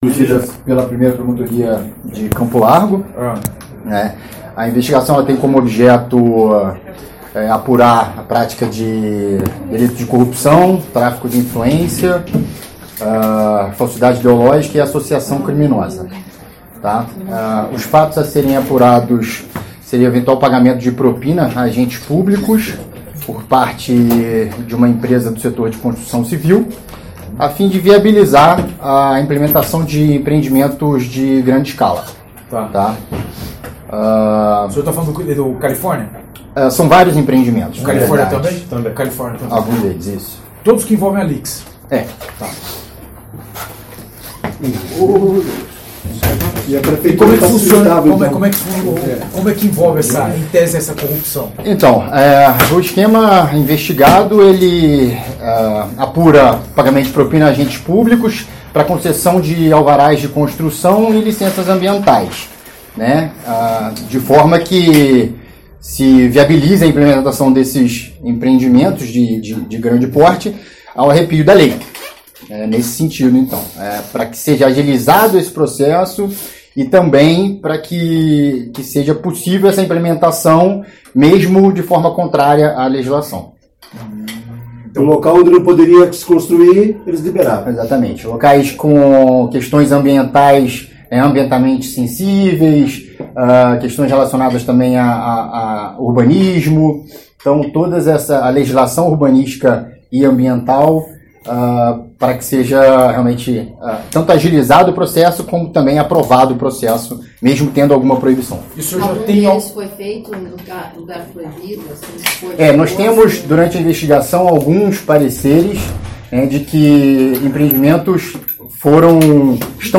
As revelações foram feitas durante entrevista do promotor Anastácio Fernandes Neto, que coordenou a operação. Segundo ele, a construtora Lix Engenharia pagava propina para obter alvarás para construção de imóveis do programa Minha Casa Minha Vida em áreas de preservação ambiental.